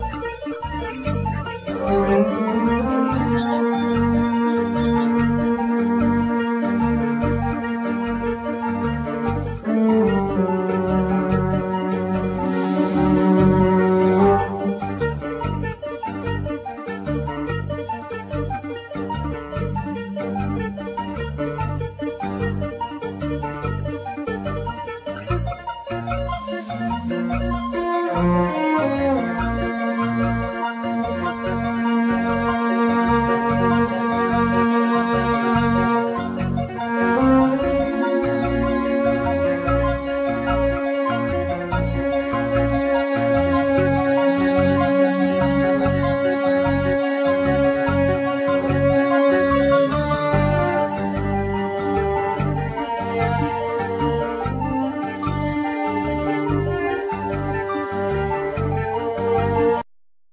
Clarinet
Keyboards,Short Waves
Cello
Percussions
Chapman Stick
Violin